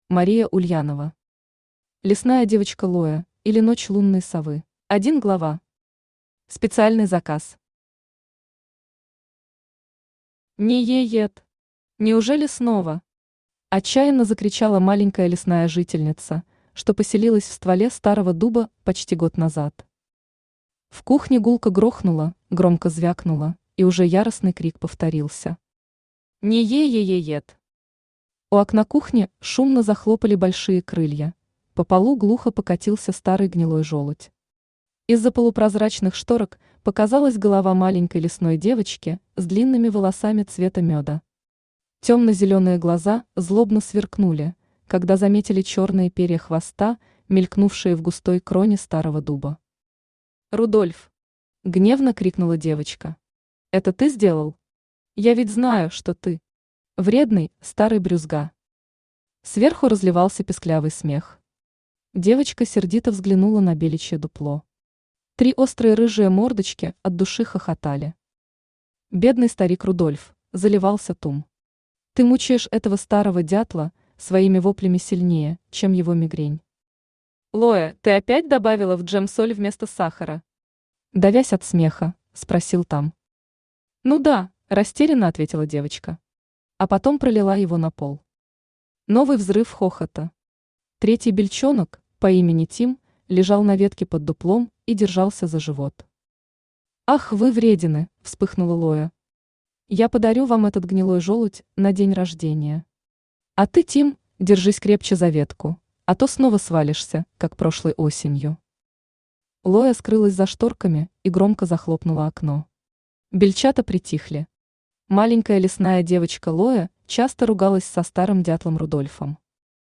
Аудиокнига Лесная девочка Лоя, или Ночь Лунной Совы | Библиотека аудиокниг
Aудиокнига Лесная девочка Лоя, или Ночь Лунной Совы Автор Мария Ульянова Читает аудиокнигу Авточтец ЛитРес.